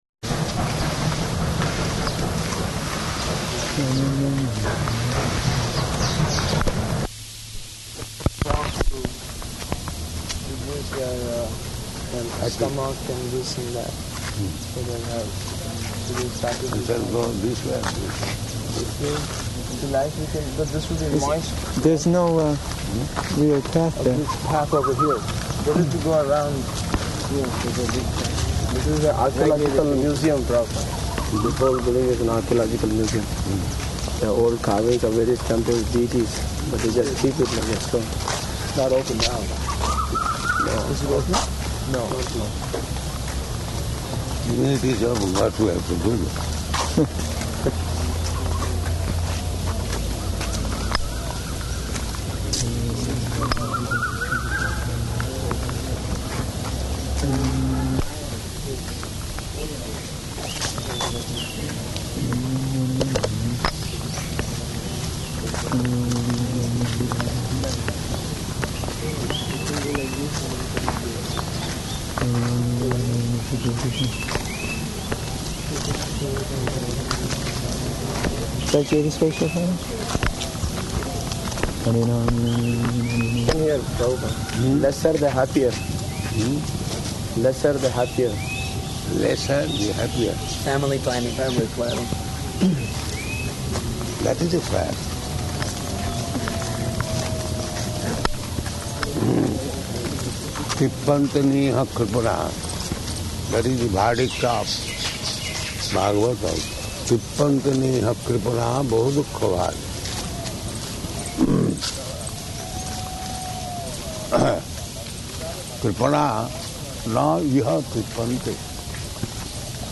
Morning Walk in Public Gardens
-- Type: Walk Dated: August 23rd 1976 Location: Hyderabad Audio file